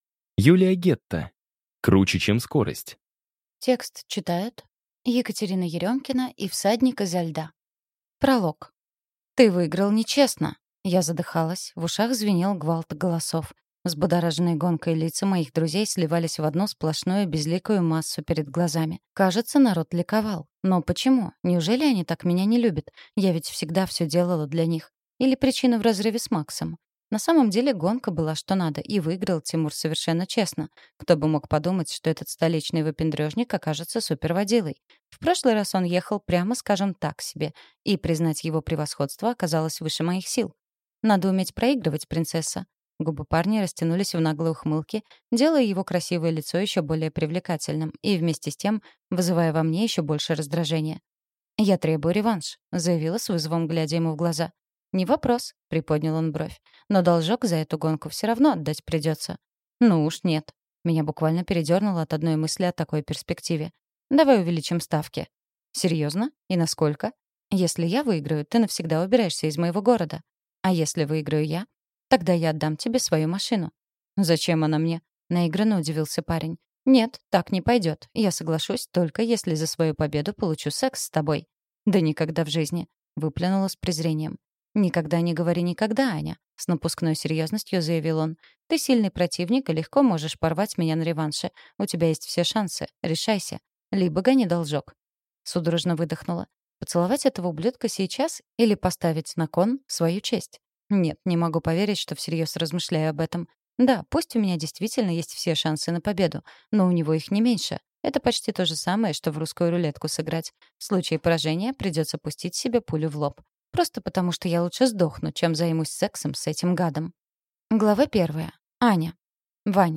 Аудиокнига Круче, чем скорость | Библиотека аудиокниг